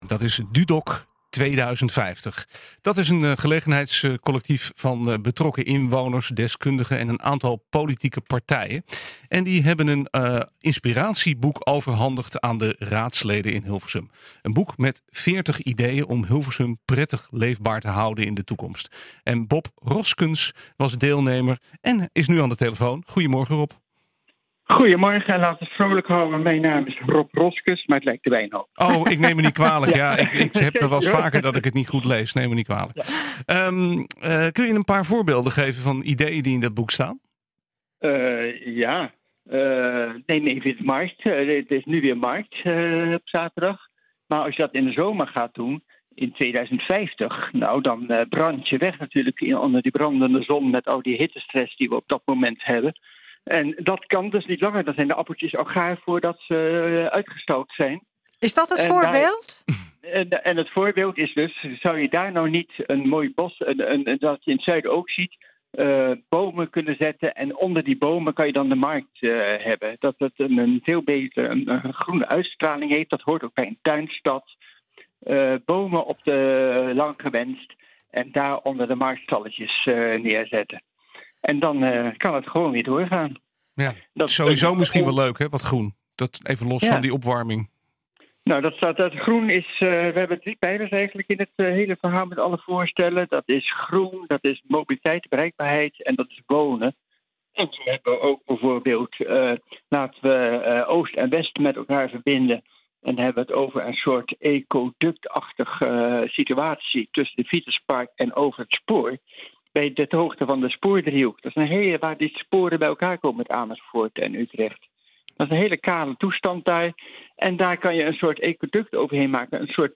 was deelnemer en nu aan de telefoon.